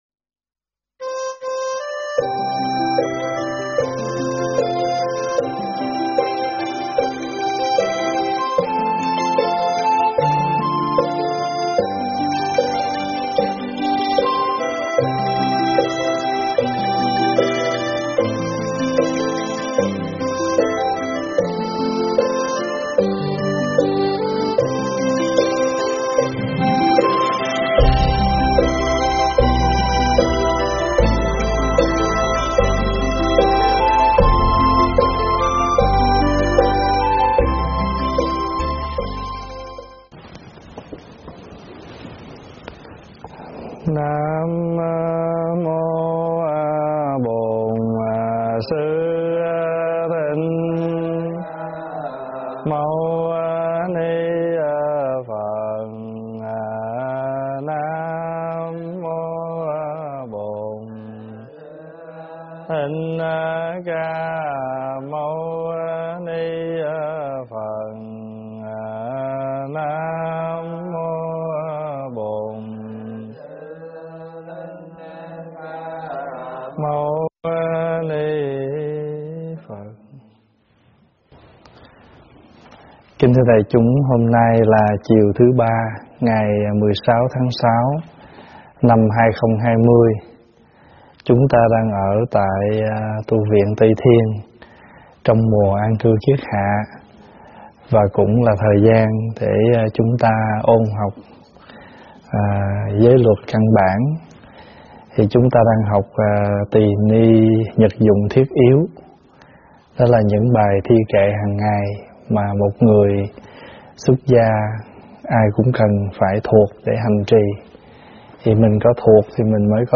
Mp3 thuyết pháp Từng Giọt Sữa Thơm 28
giảng tại Tv Trúc Lâm